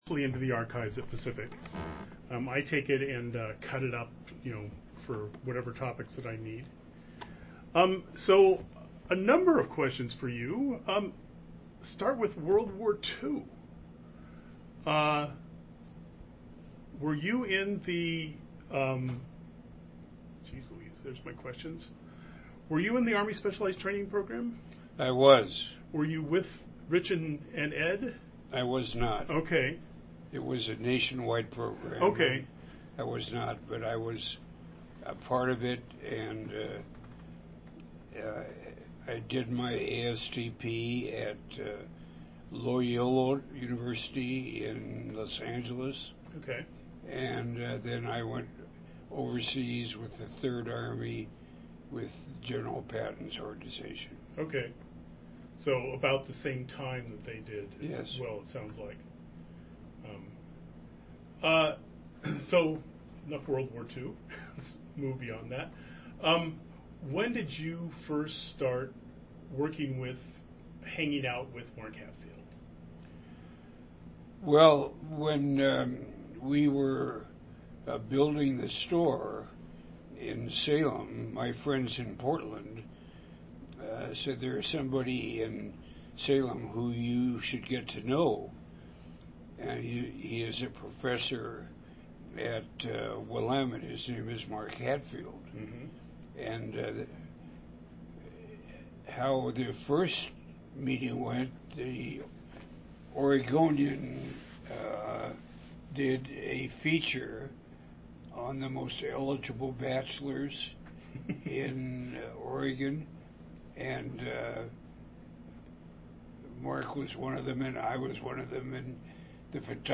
3e6bc9f0c1dc84e85f23c8f18d3db16e8ffaf917.mp3 Title Gerry Frank interview on Atiyeh Description An interview of Gerry Frank on the topic of Oregon Governor Vic Atiyeh, recorded on August 17, 2015.